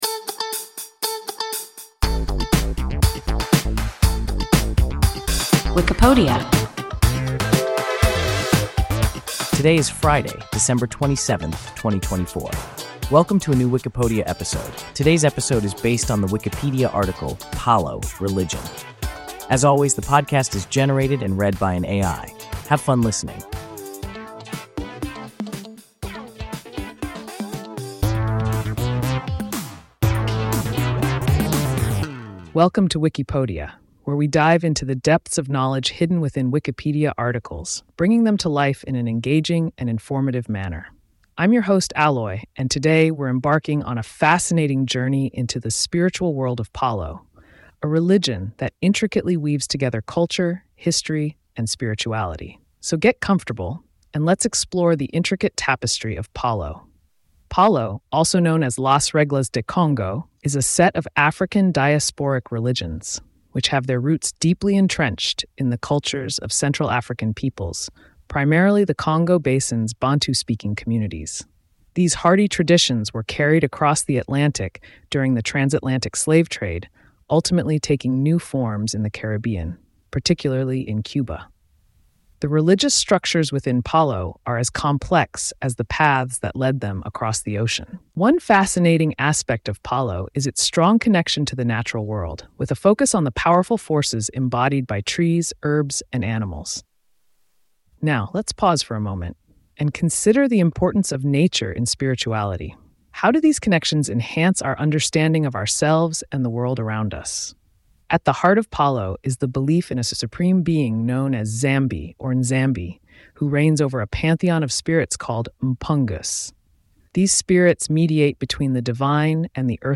Palo (religion) – WIKIPODIA – ein KI Podcast